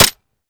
weap_golf21_fire_last_plr_mech_02.ogg